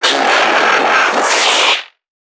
NPC_Creatures_Vocalisations_Infected [120].wav